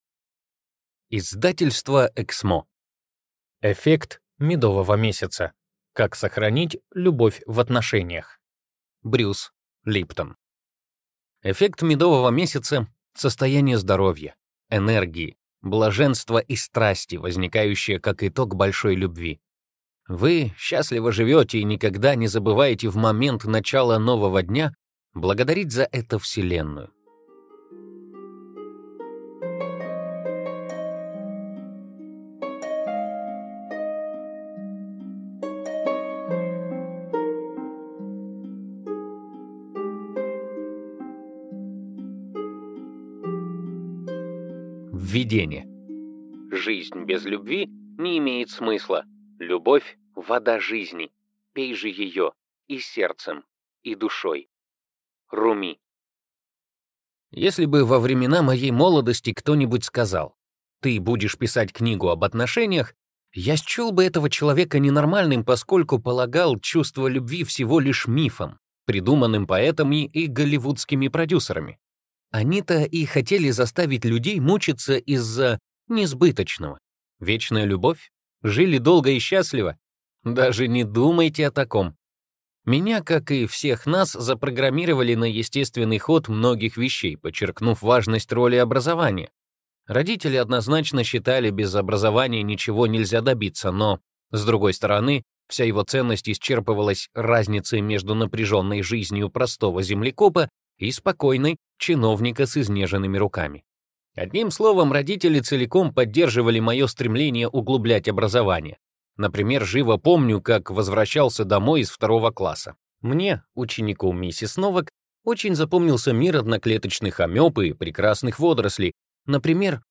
Аудиокнига Эффект медового месяца. Как сохранить любовь в отношениях | Библиотека аудиокниг